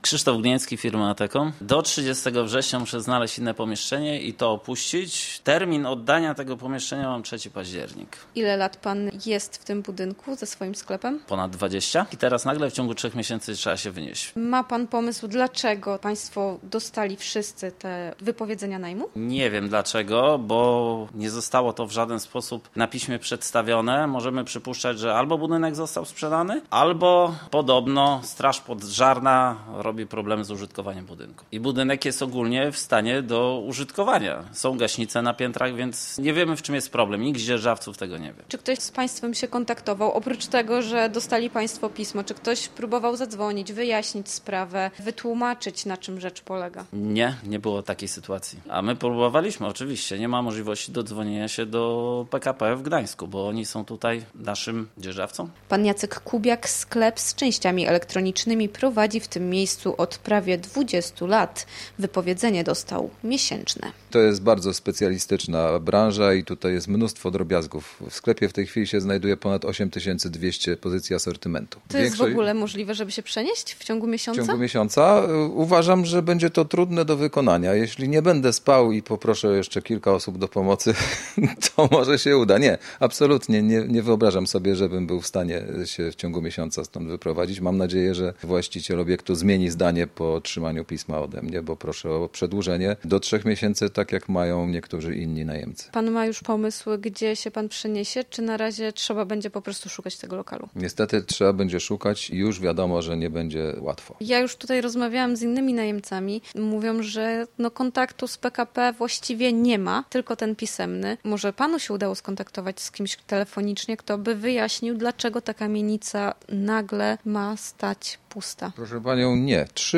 Posłuchaj materiału naszej dziennikarki: